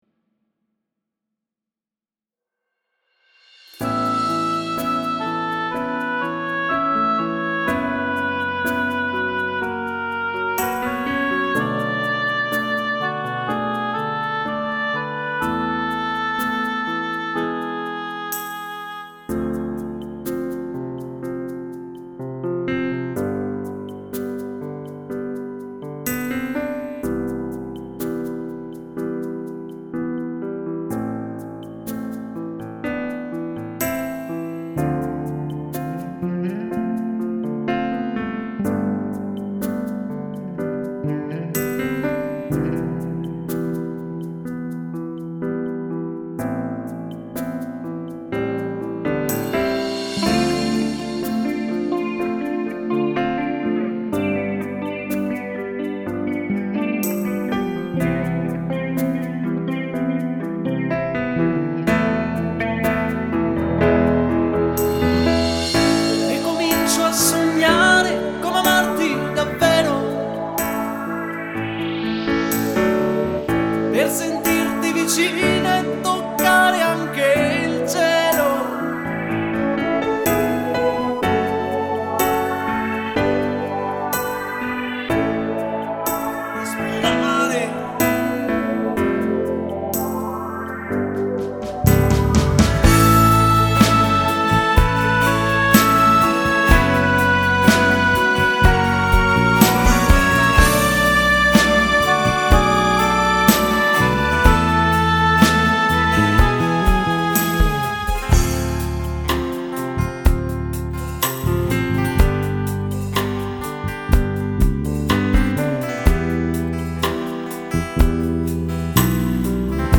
Moderato lento
Uomo